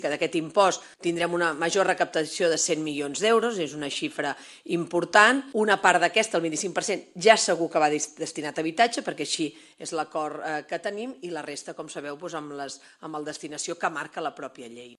Així ho ha acordat el Govern de Salvador Illa amb els Comuns, i que una quarta part dels ingressos que es generin es destinin a combatre l’emergència habitacional. La consellera d’Economia, AlÍcia Romero, ho anunciava ahir en roda de premsa.